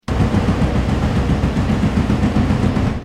Dampfende Lokomotive, rhythmisch und kraftvoll.mp3